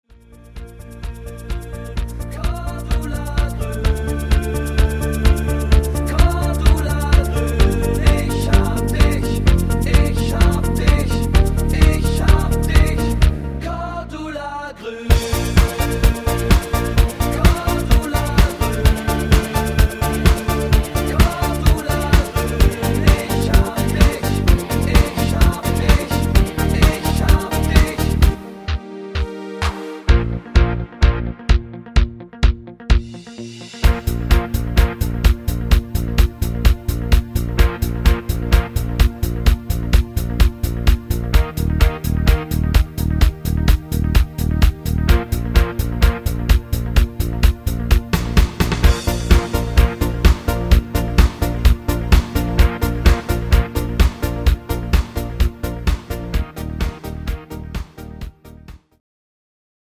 Discofox Version